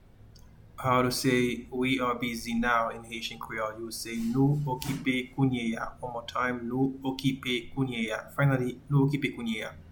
Pronunciation:
We-are-busy-now-in-Haitian-Creole-Nou-okipe-kounye-a.mp3